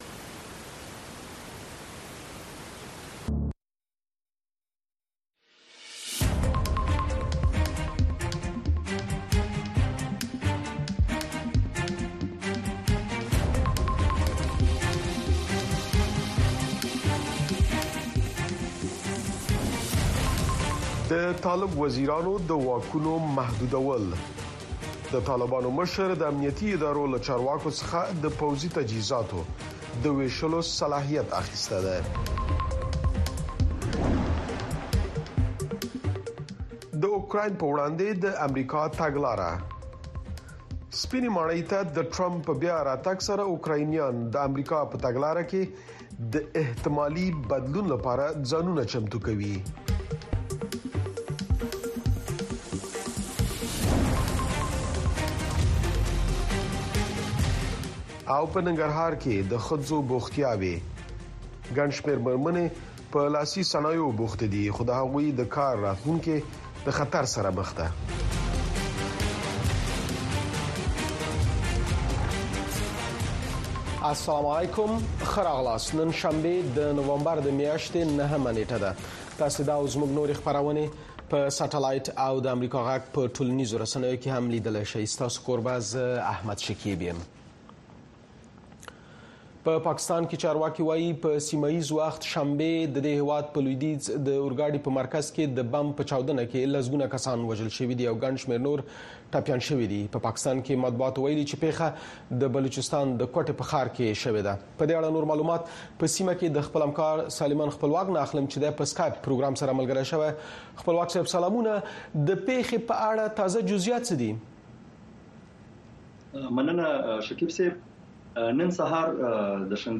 د اشنا خبري خپرونه